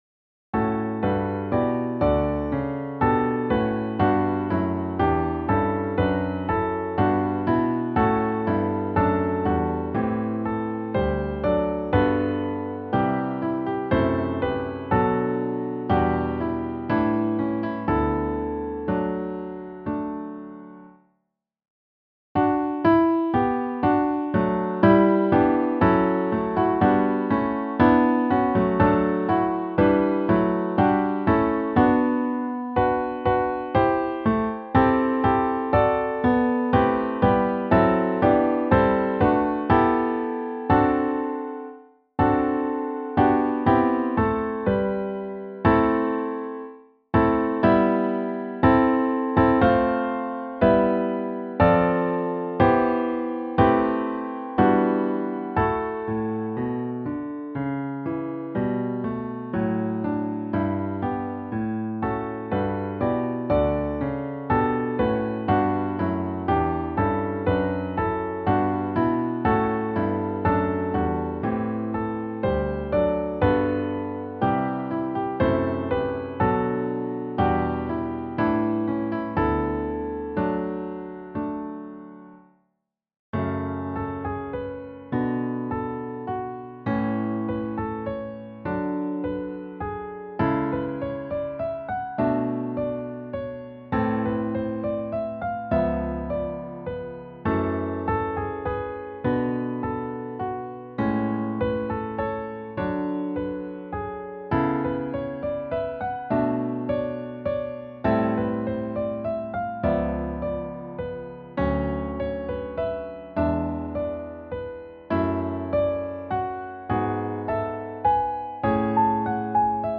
• Music Type: Choral
• Voicing: Congregation, SATB
• Accompaniment: Organ